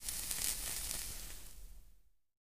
cigarette_random.ogg